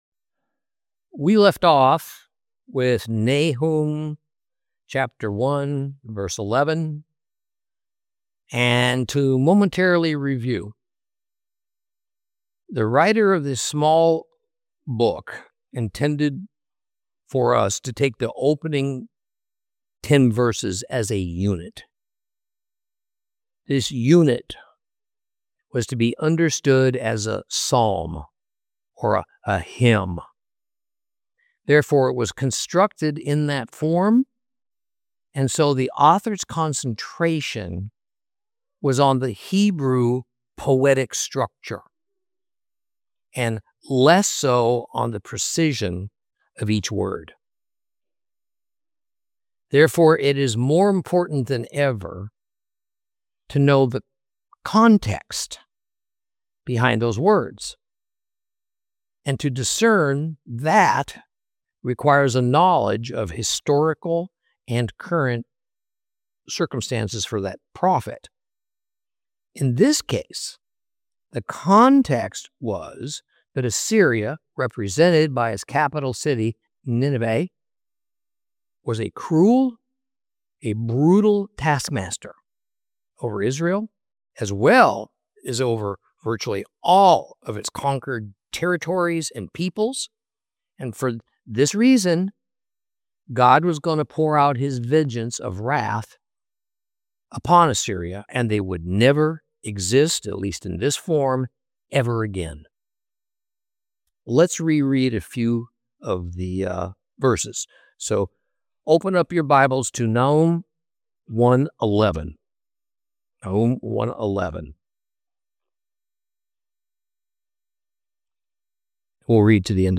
Teaching from the book of Nahum, Lesson 4 Chapters 1 & 2